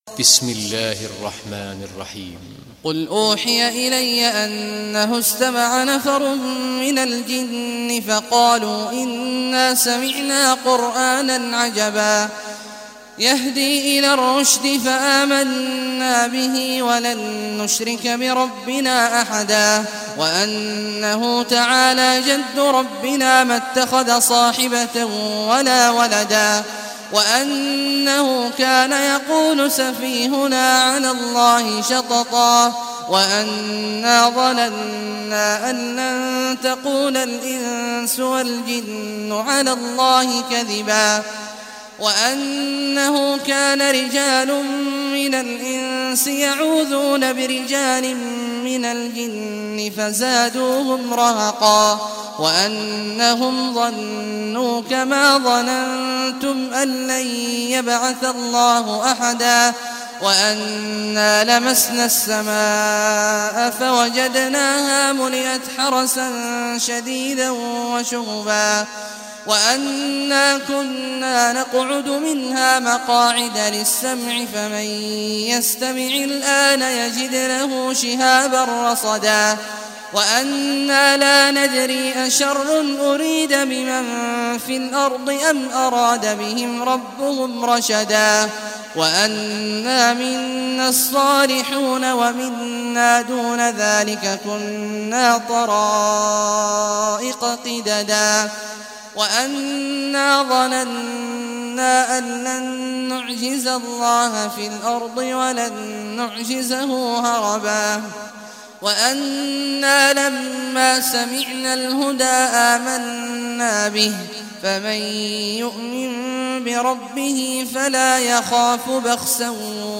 Surah Al-Jinn Recitation by Sheikh Awad al Juhany
Surah Al-Jinn, listen or play online mp3 tilawat / recitation in Arabic in the beautiful voice of Sheikh Abdullah Awad al Juhany.